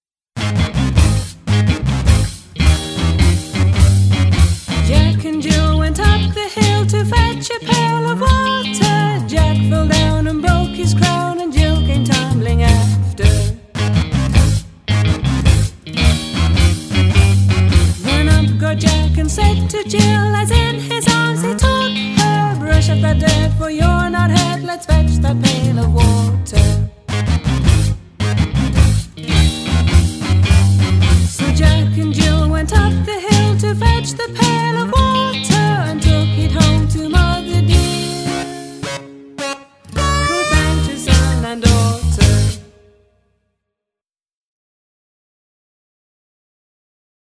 TraditionalFun & Punk